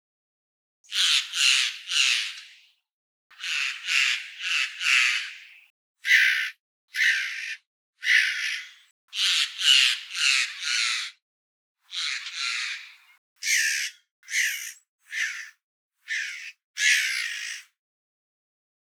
Eichelhäher Ruf
Eichelhaeher-Ruf-Voegel-in-Europa.wav